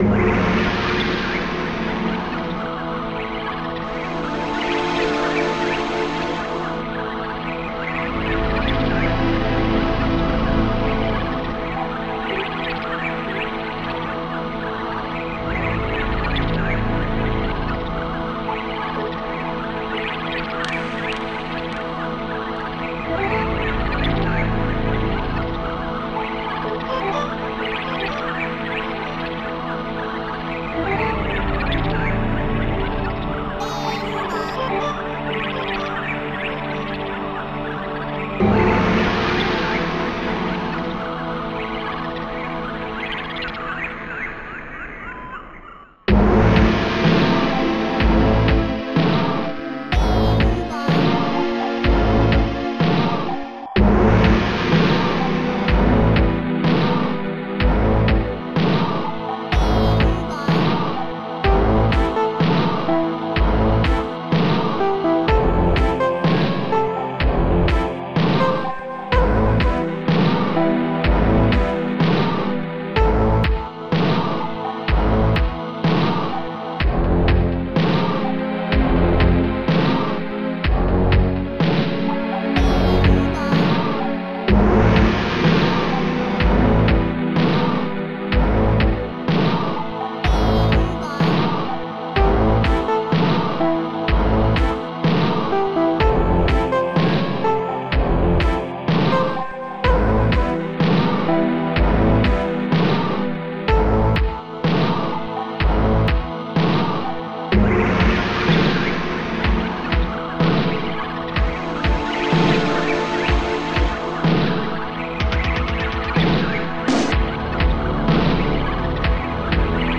Protracker and family
st-07:tecnobassdrum
st-05:megabass
ST-03:longsynth3
st-03:jarresynth